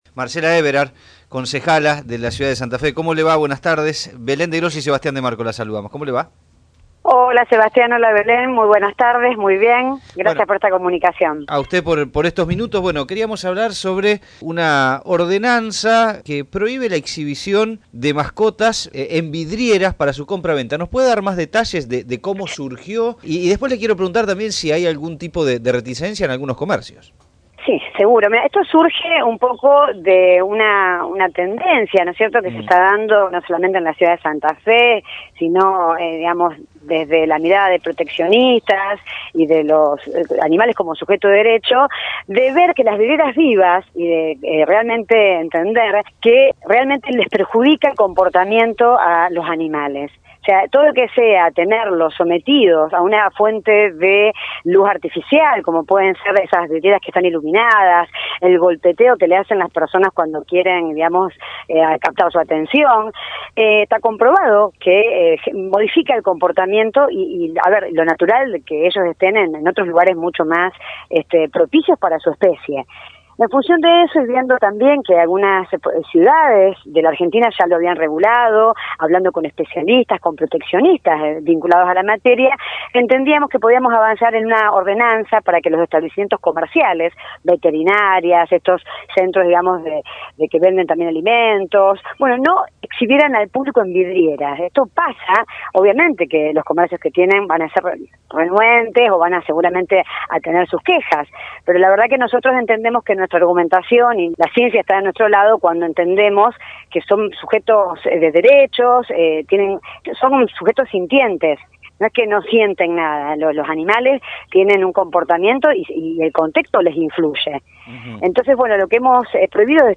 Tren Urbano conversó con la concejala Marcela Aeberhard autora de la ordenanza que prohíbe la exhibición de animales en vidrieras para su venta.